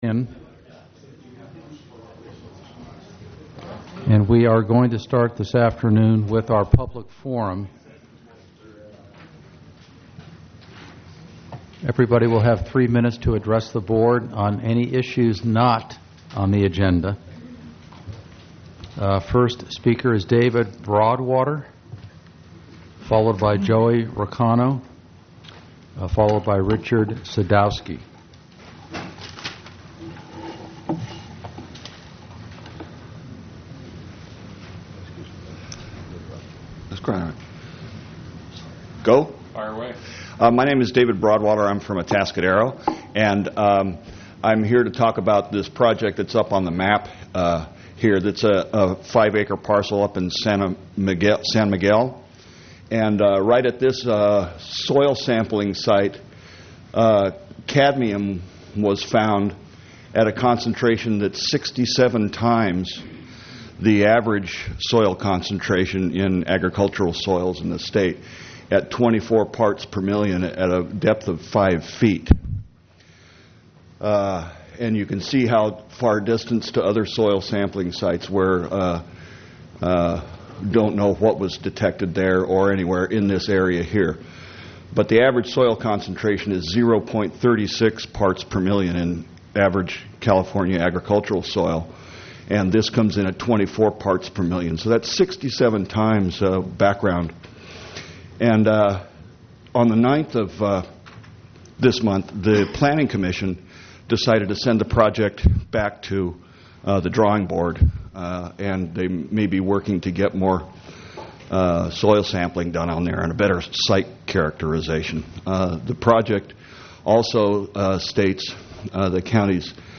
item18_public_forum.mp3